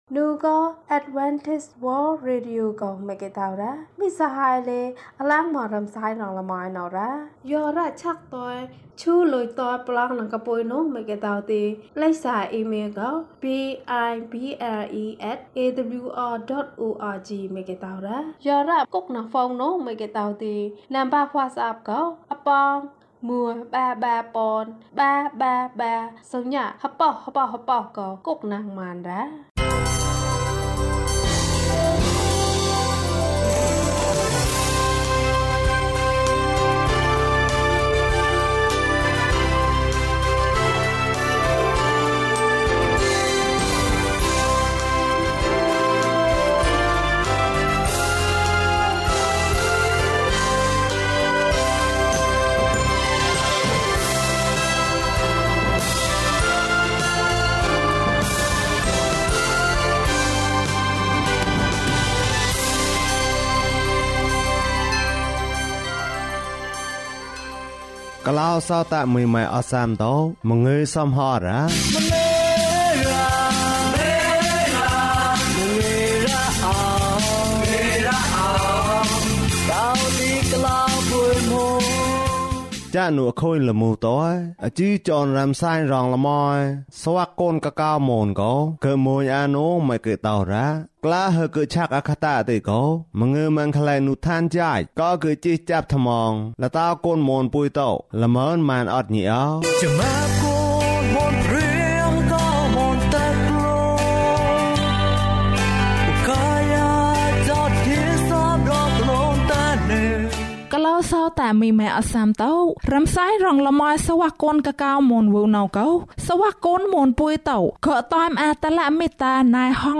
အသက်ရှင်သောဘုရားသခင်။ ၂ ကျန်းမာခြင်းအကြောင်းအရာ။ ဓမ္မသီချင်း။ တရားဒေသနာ။